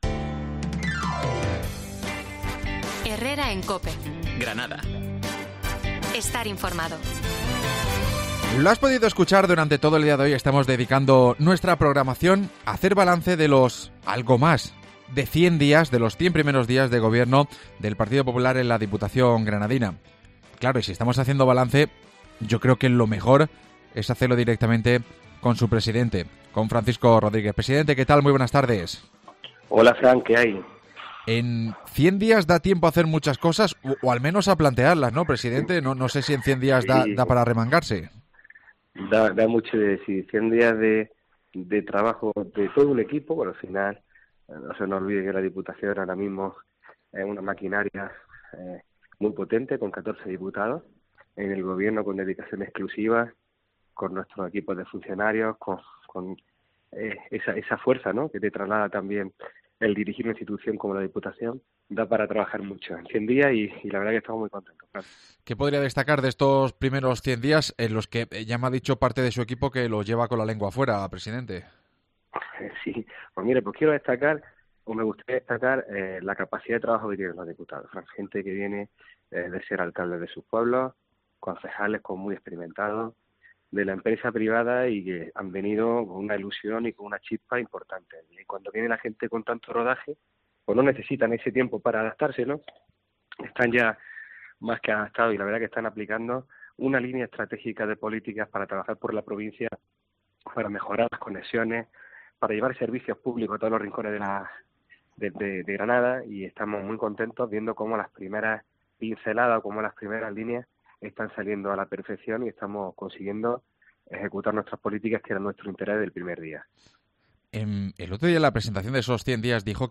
Cien días de gobierno provincial: Entrevista a Francisco Rodríguez, presidente provincial